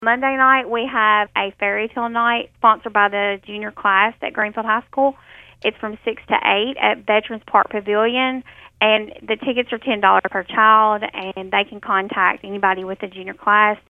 City Recorder Jerica Spikes tells Thunderbolt News about year two of the fun filled fairytale night.